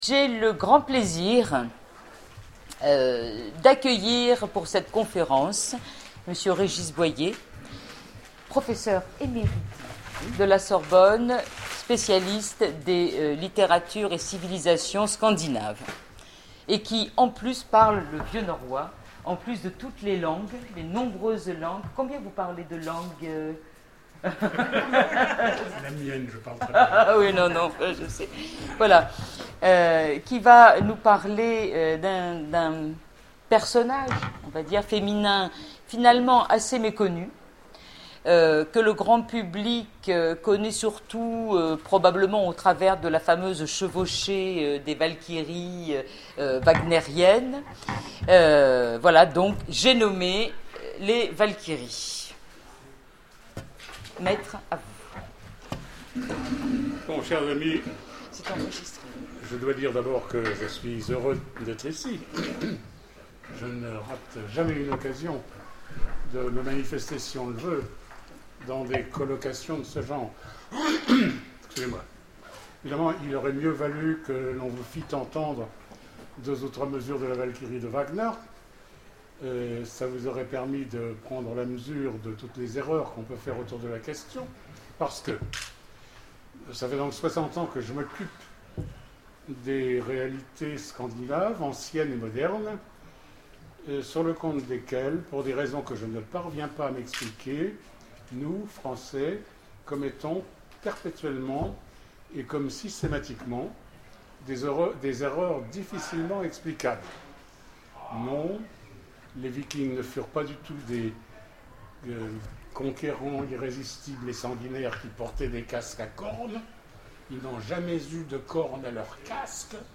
Imaginales 2012 : Conférence Les Valkyries